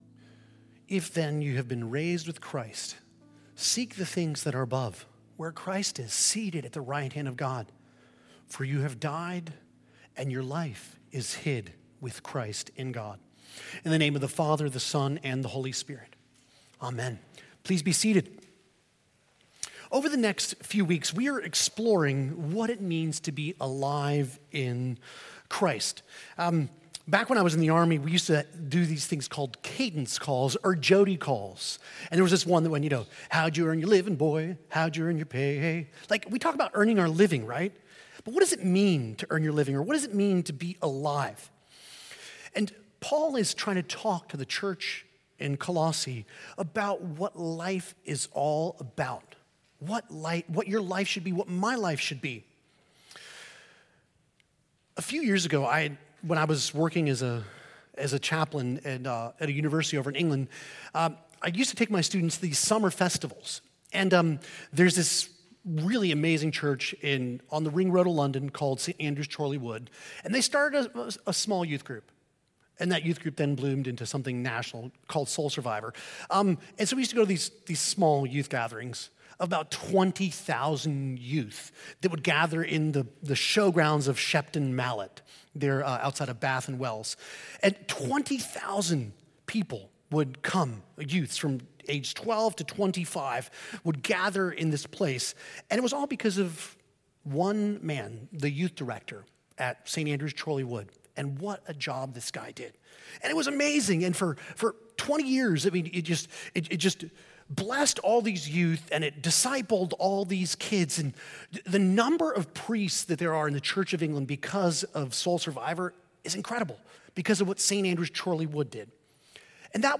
This sermon explores the transformative truth of Colossians 3:1–11, emphasizing that the Christian life is not merely a list of dos and don’ts but a radical reorientation of identity and purpose in Christ.